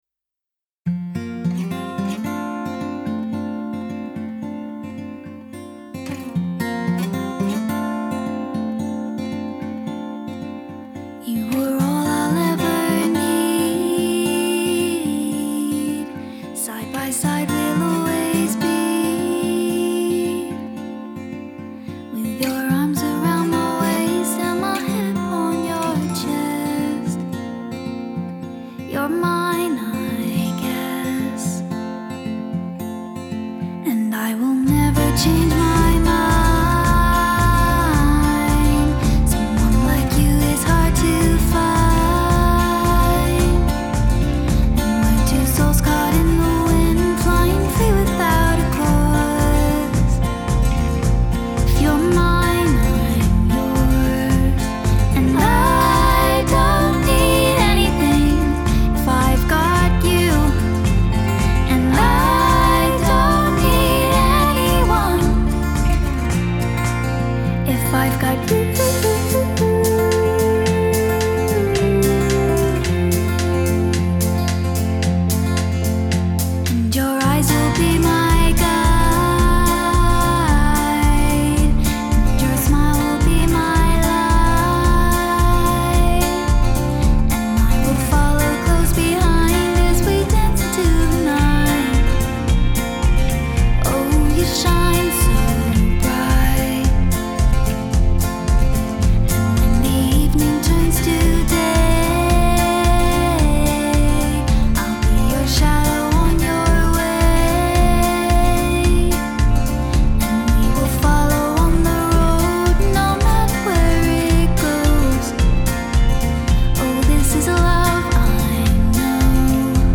Genre: Electronic, Pop